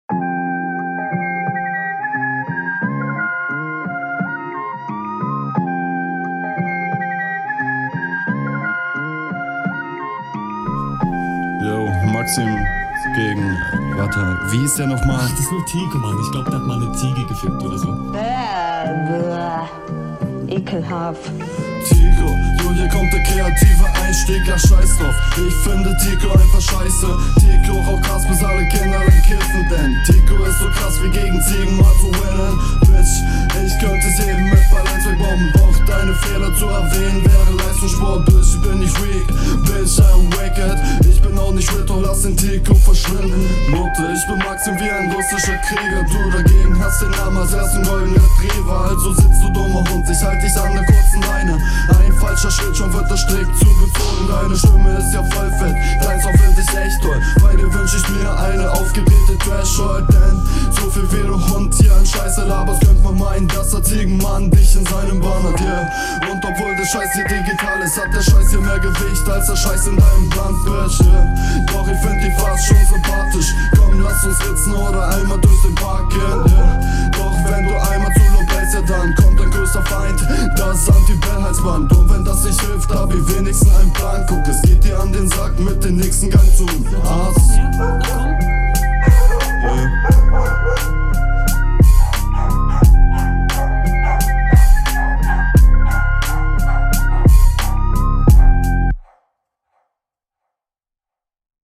Schöner entspannter Beat mit schönem Vibe.
intro recht funny. stimme könnte mehr im vordergrund sein. find auch den klang nicht soo …